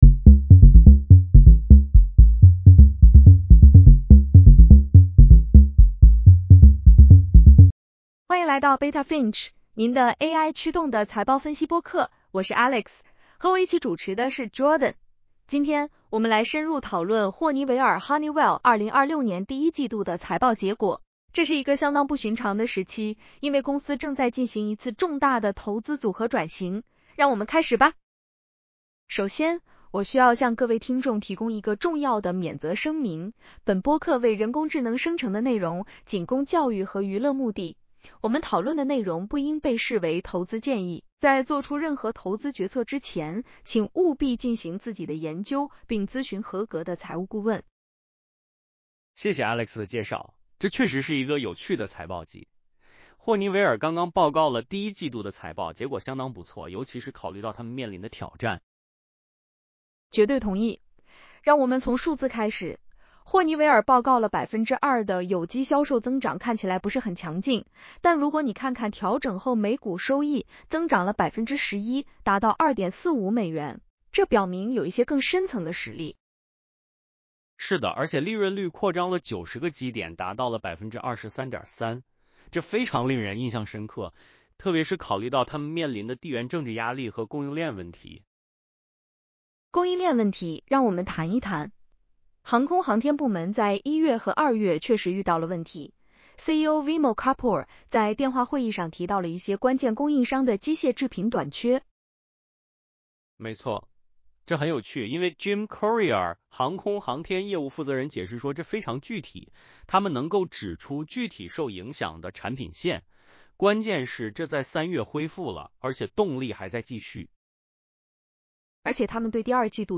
首先，我需要向各位听众提供一个重要的免责声明：本播客为人工智能生成的内容，仅供教育和娱乐目的。